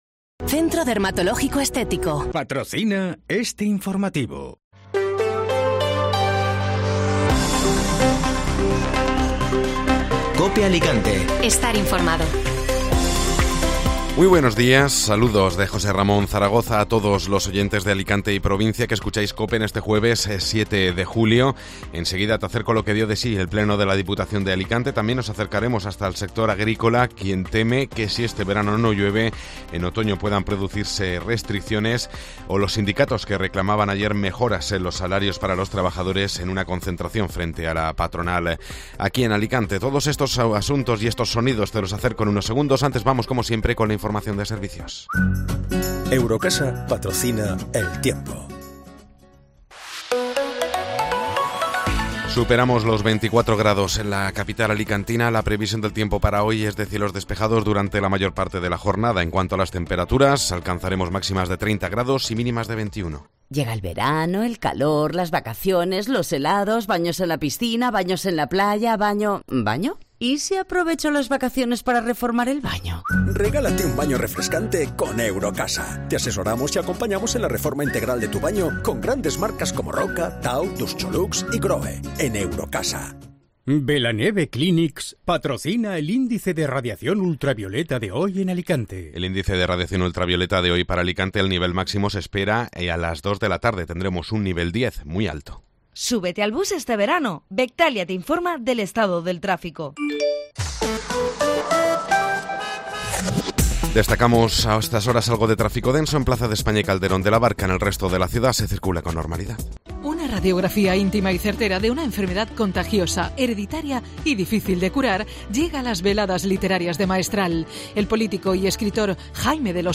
Informativo Matinal (Jueves 7 de Julio)